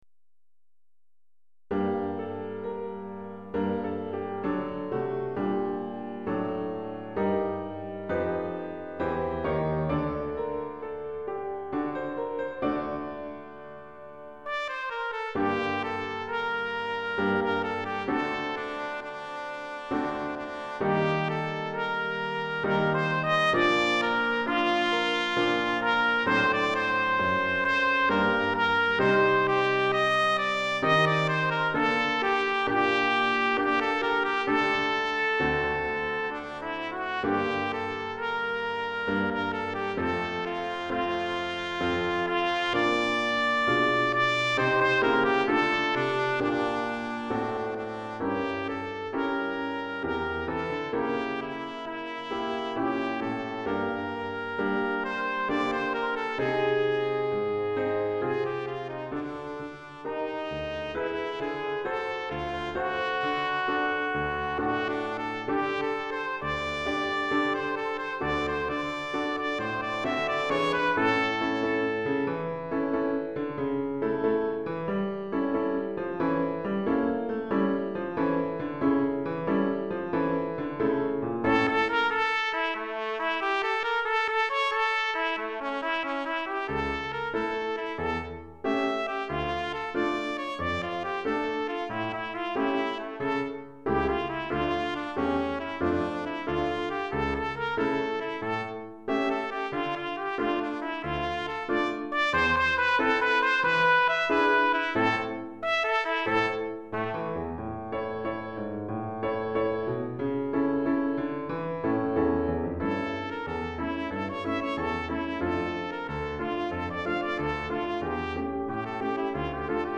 Répertoire pour Trompette ou cornet